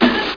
00409_Sound_bounce2
1 channel